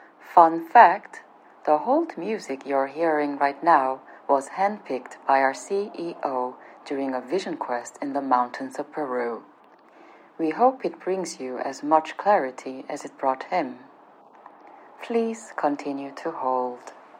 Operator_filtered9.mp3